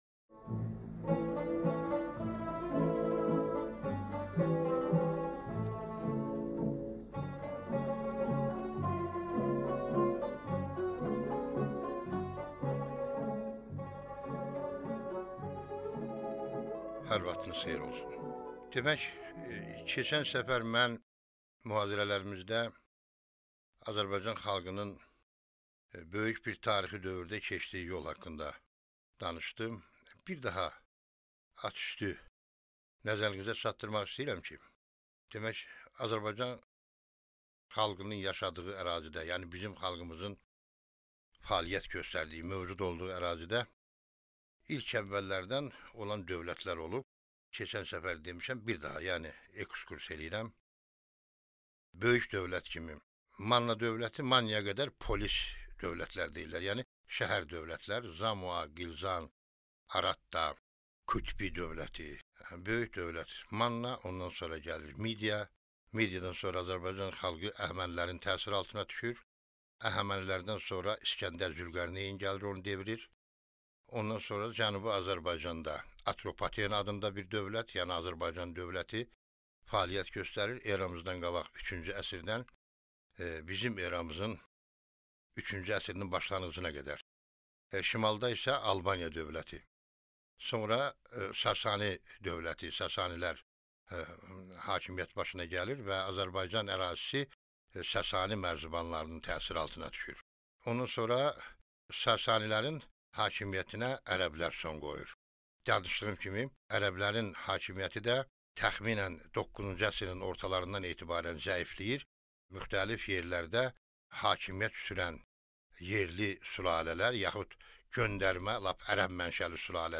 Аудиокнига Eldənizlər dövləti | Библиотека аудиокниг